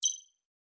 Epic Holographic User Interface Click 5.wav